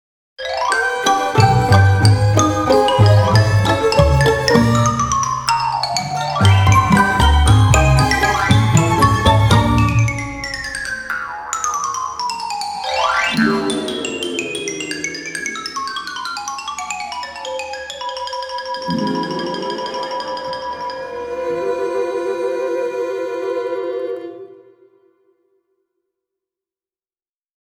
Original Film Score